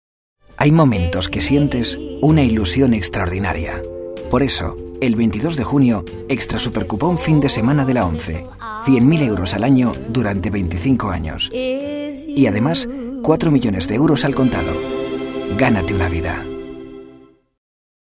Sprecher spanisch für Hörfunk und Werbung
Sprechprobe: eLearning (Muttersprache):